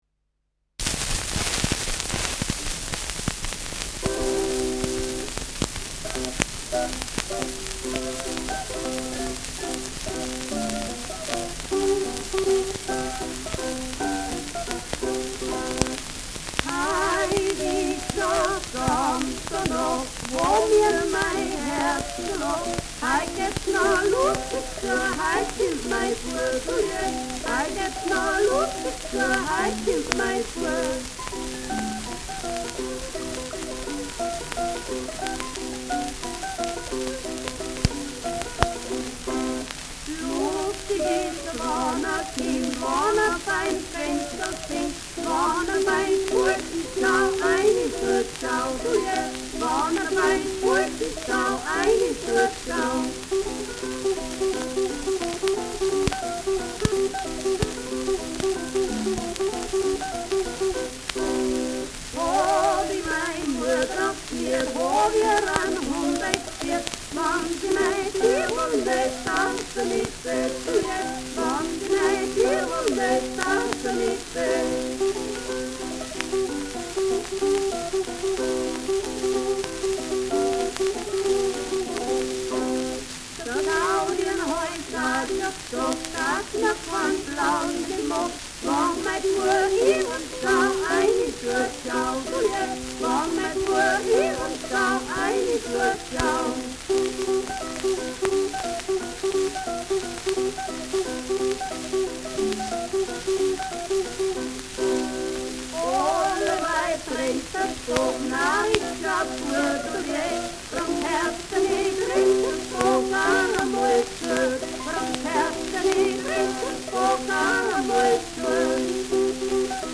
Schellackplatte
präsentes Rauschen : präsentes Knistern : gelegentliches Knacken : leichtes Leiern
[unbekannte musikalische Begleitung] (Interpretation)